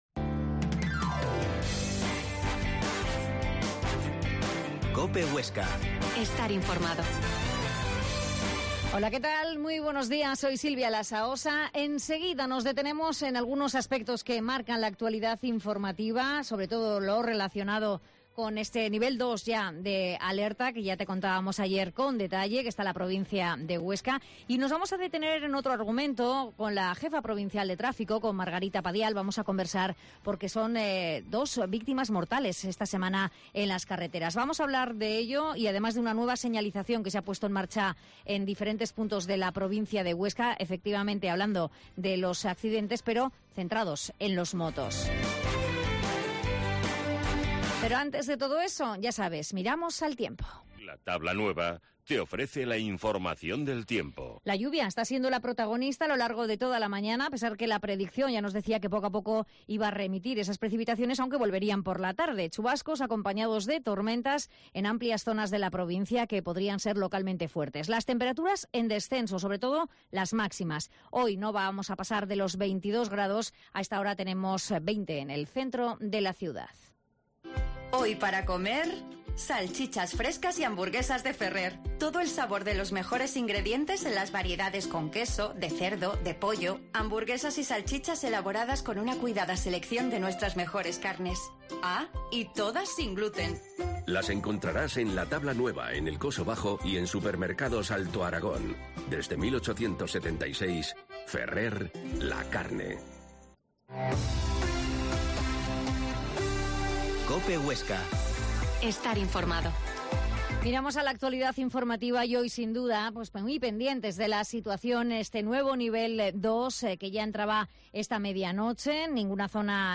Herrera en Cope Huesca 12,50h. Entrevista a la jefa de Tráfico en Huesca